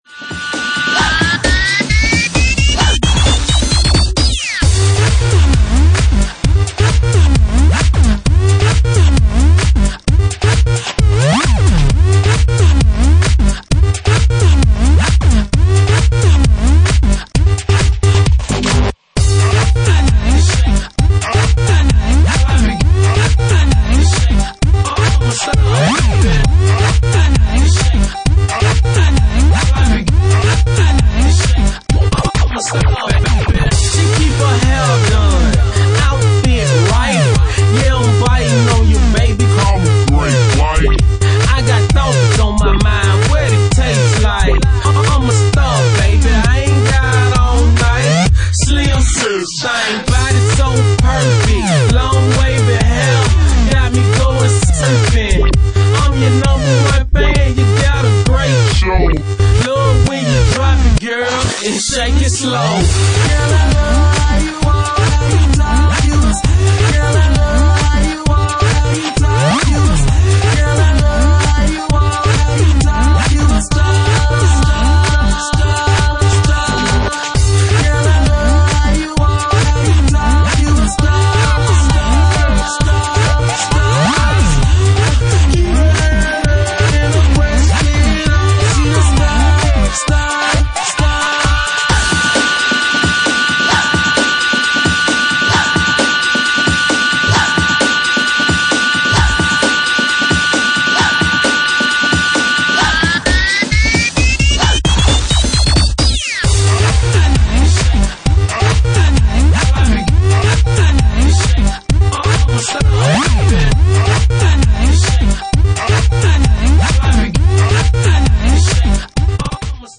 Dub remix